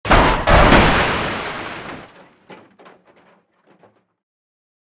crash.ogg